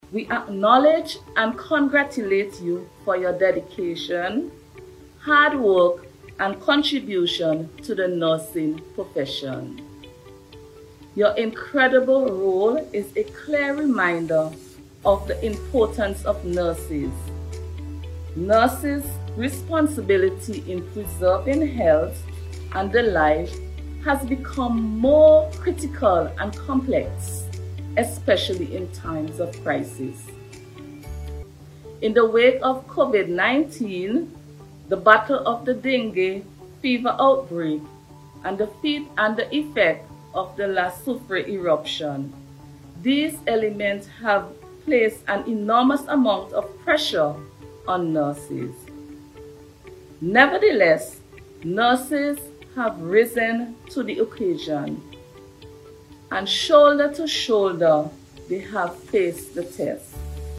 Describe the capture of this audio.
commended the Nurses for their dedication to the Nursing Profession, during her address to observe the Day yesterday.